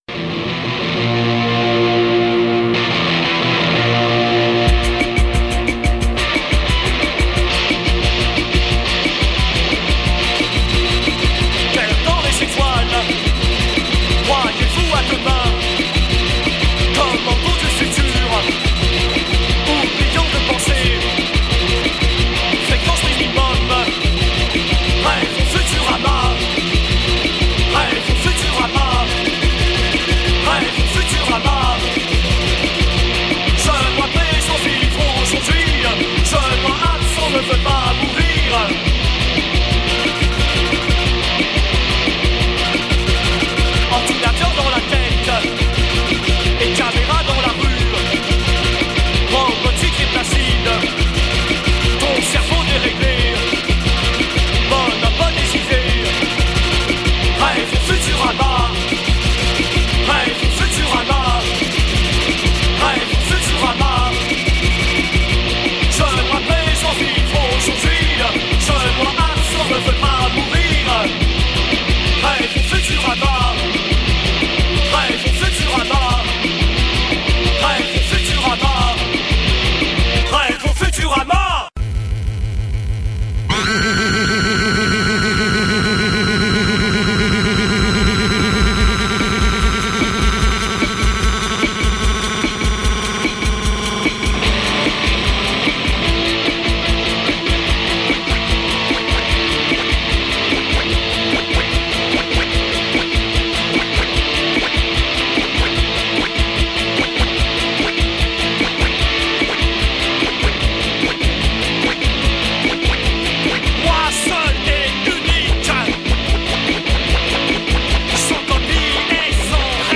Parisian Punks